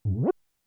lift.wav